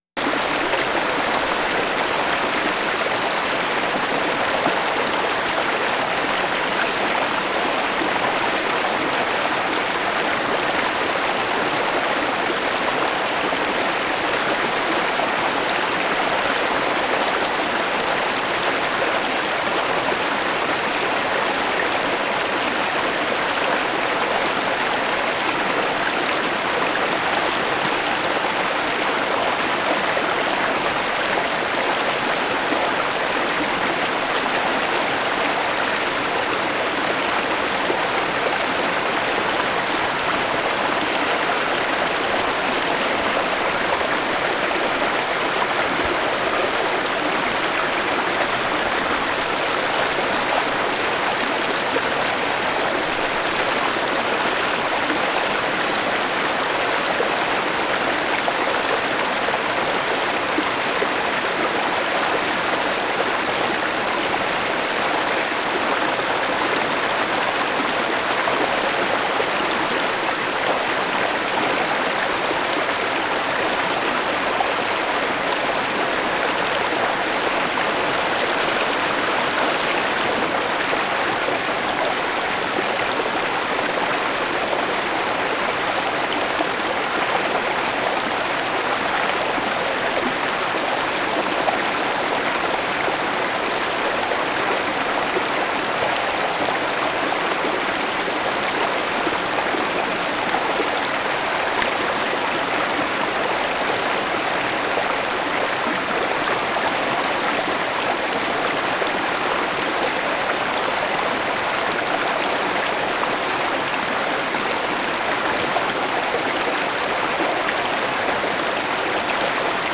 HORSESHOE BAY, SURROUNDING AREA NOV. 3, 1991
small rapids 4:50
10. nice left to right events, musical water sounds, mic direction movement at 3:30, plane in distance at 3:45